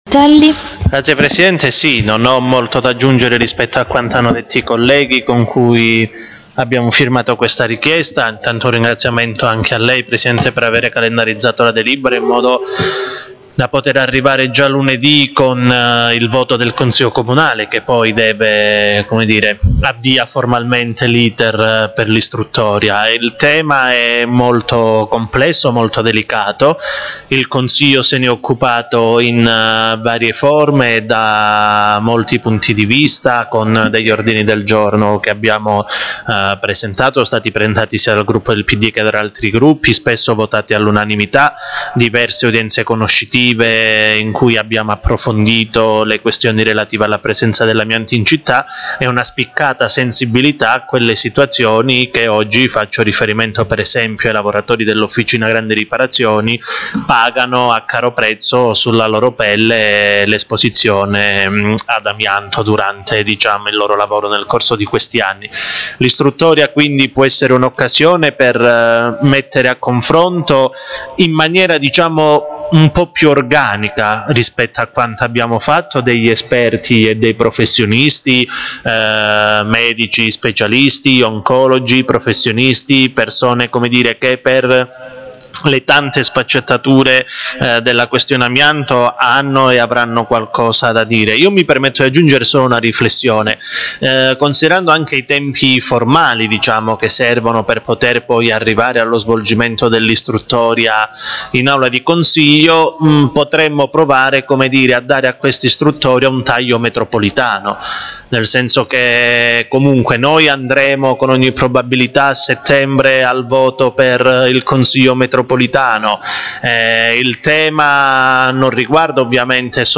Il capogruppo Francesco Critelli interviene in Aula